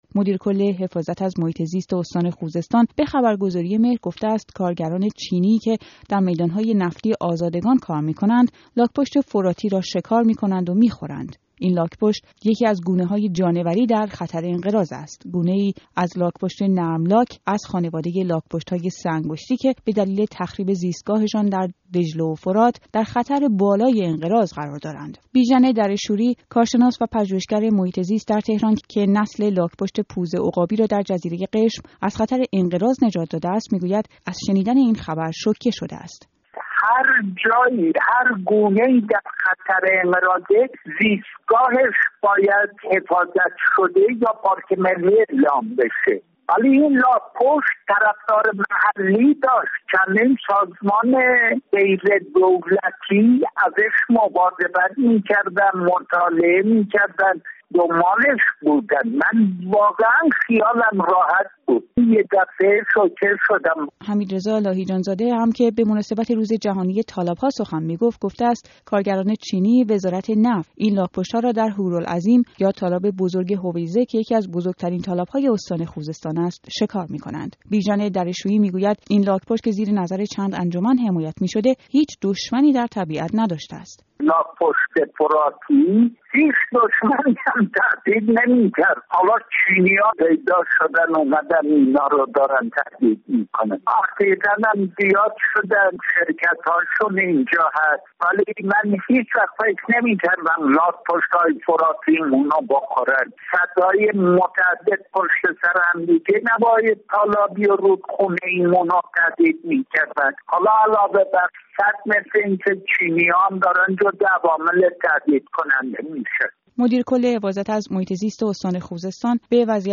یک خانه یک زمین؛ گزارشی از تهدیدی تازه برای لاک‌پشت‌های فراتی: "کارگران چینی"